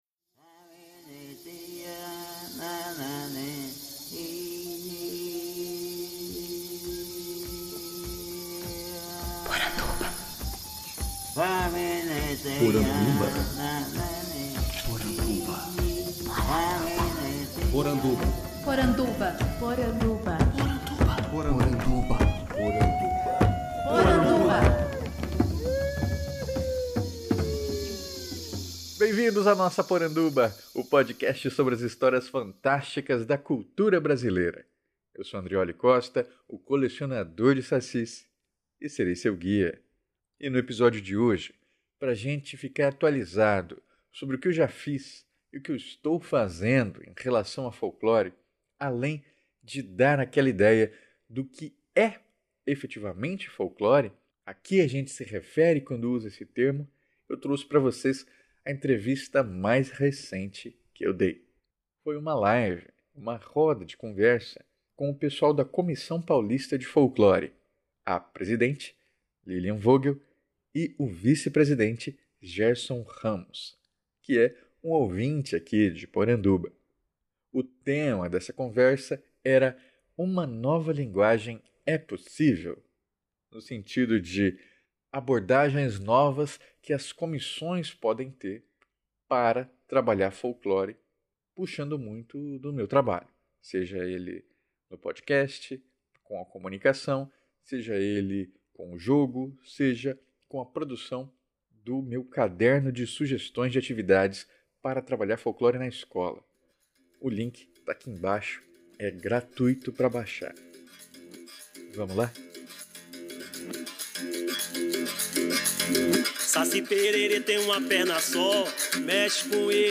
Podcast com entrevistas durante o Congresso Nacional de Folclore.